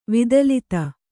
♪ vidalita